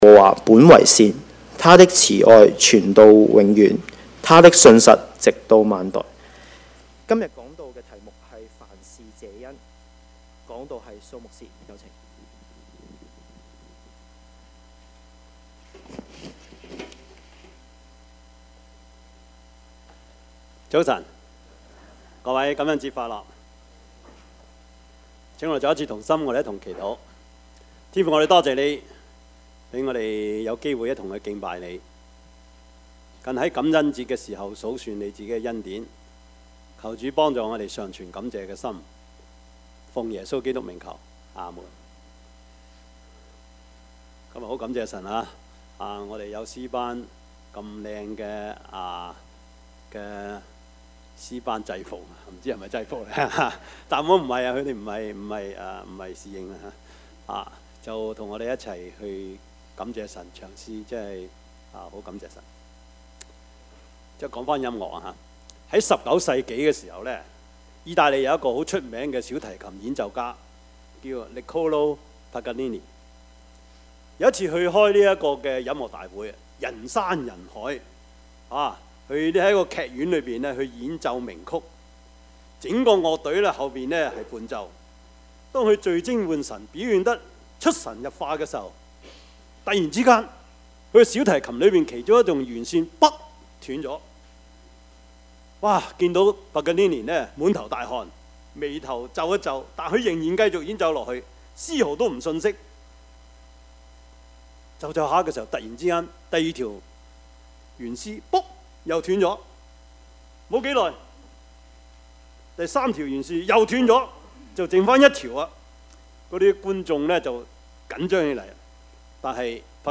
Service Type: 主日崇拜
Topics: 主日證道 « 狐狸與小雞 第一首聖誕歌 »